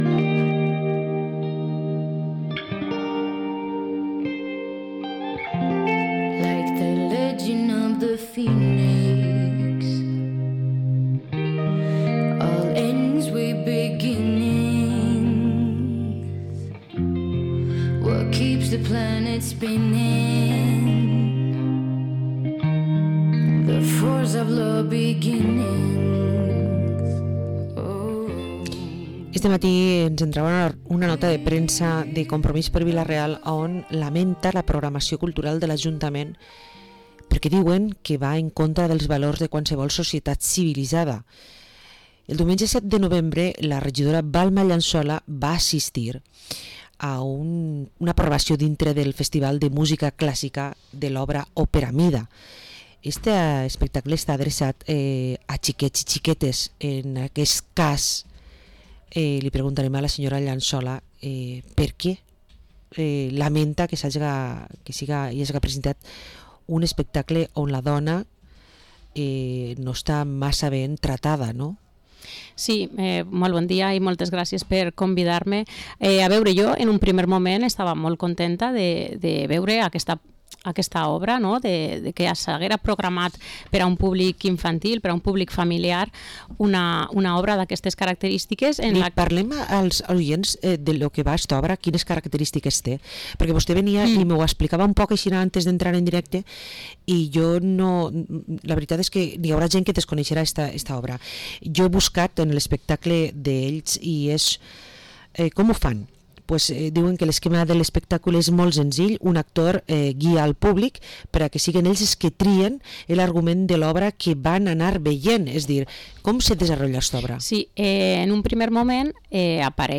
Entrevista a la concejala de Compromís per Vila-real, Balma Llansola